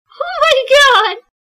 На этой странице собраны звуки с фразой «Oh my god» в разных эмоциональных оттенках: удивление, восторг, шок.
3. О май гад — восторг девушки от нового телефона